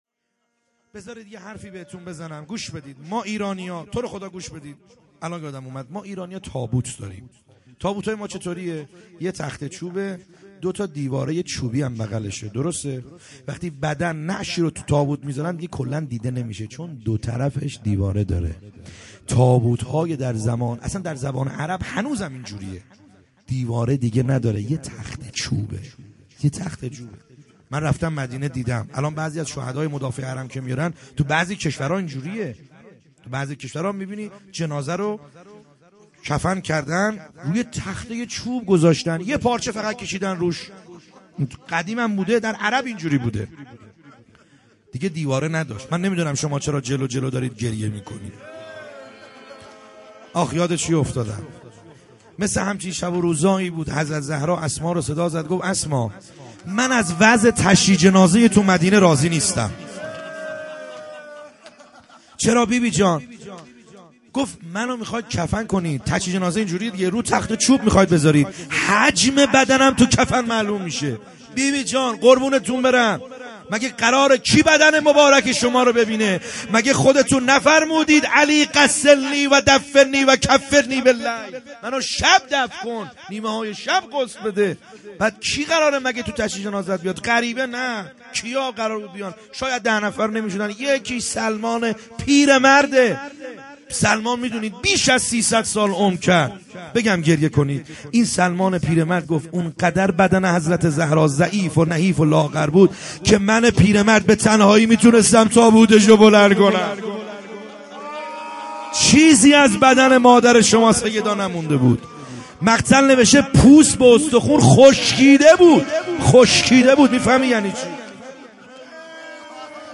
خیمه گاه - بیرق معظم محبین حضرت صاحب الزمان(عج) - روضه | امام حسن عليه السلام